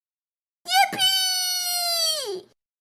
Yippee Meme Sound Effect sound effects free download